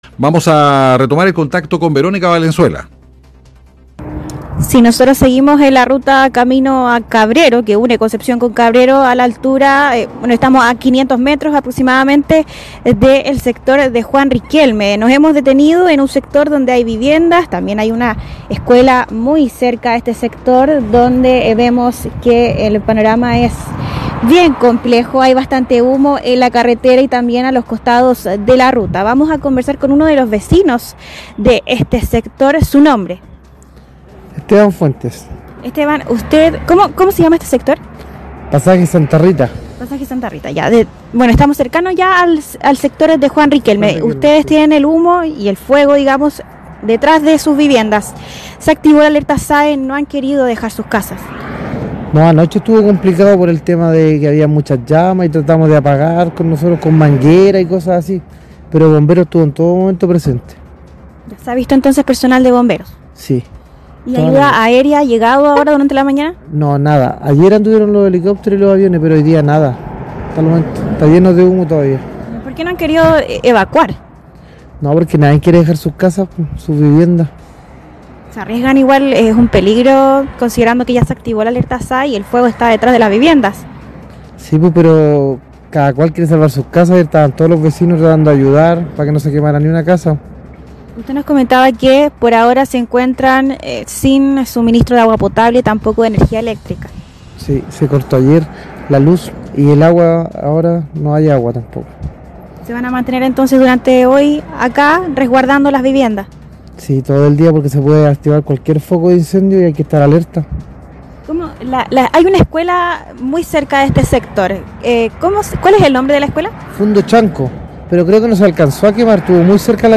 desde el sector San Jorge:
evacuacion-san-jorge-palomares.mp3